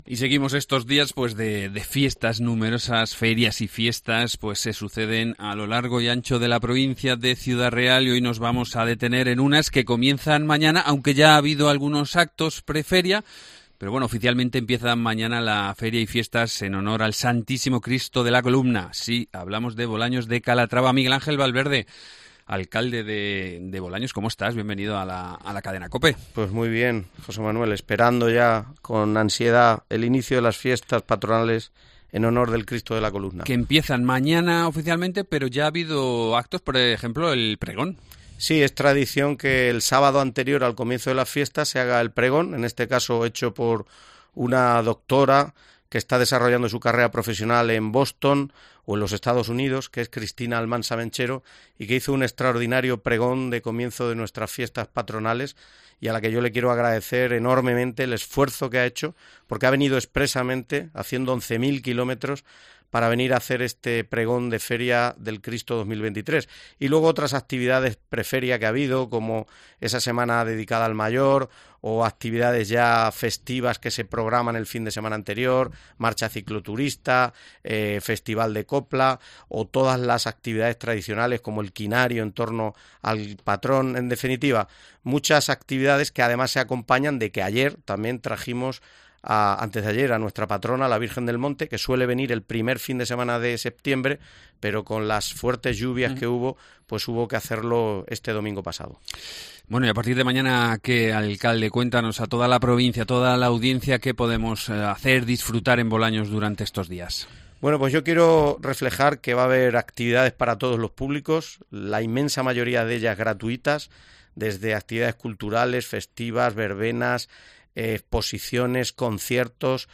Miguel Ángel Valverde, alcalde de la localidad, ha presentado en Cope la programación de las fiestas que arrancan mañana